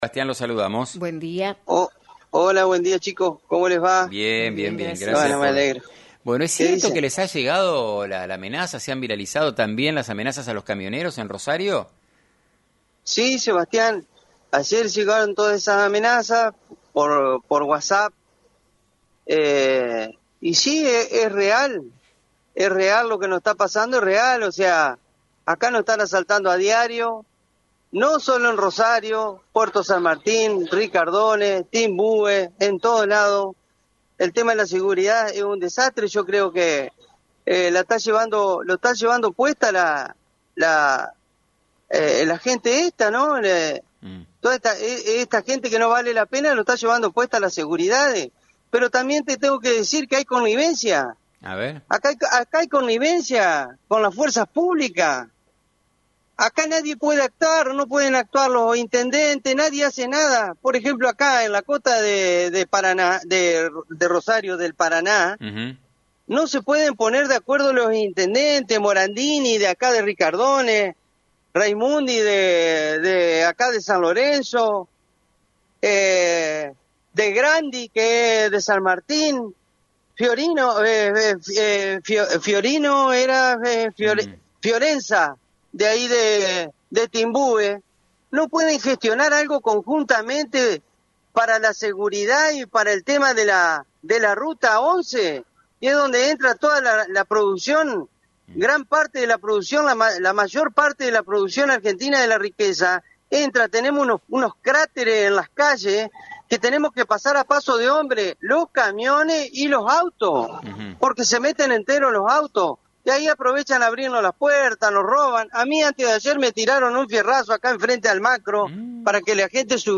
FM90.3